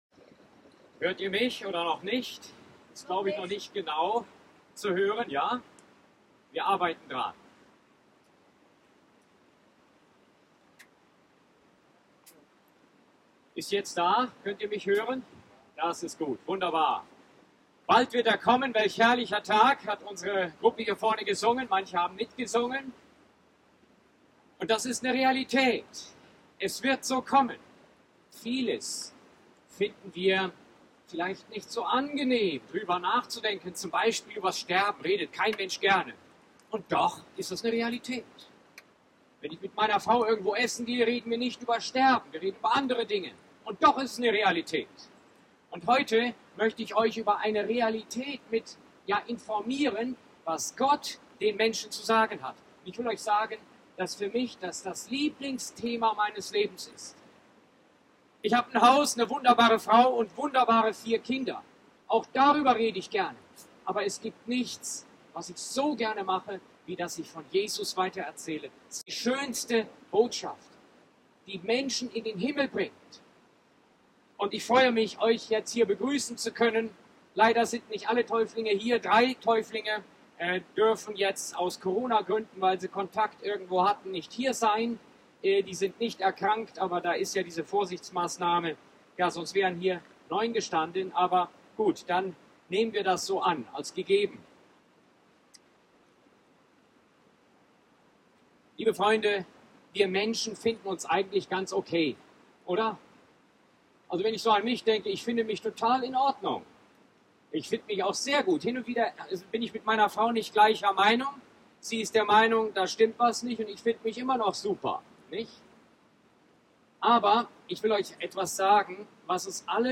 Taufpredigt 2020